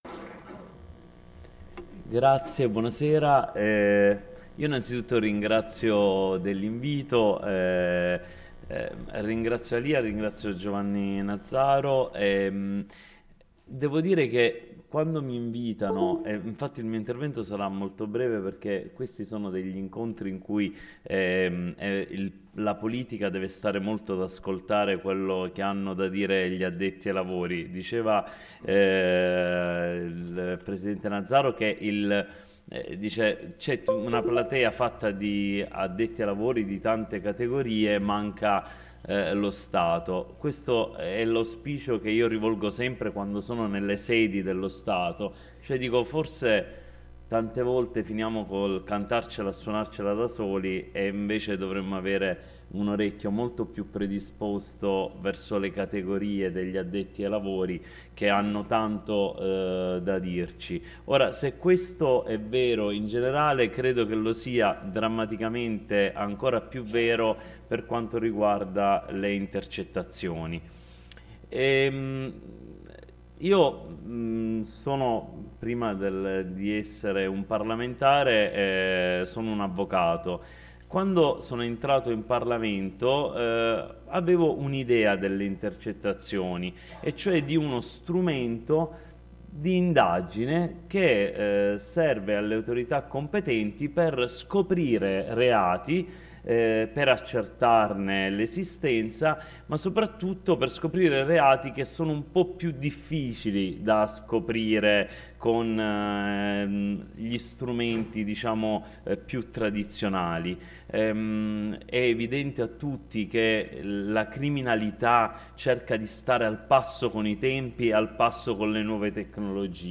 Intervento dell’On. Alfonso Bonafede all’edizione 2017 della LIA.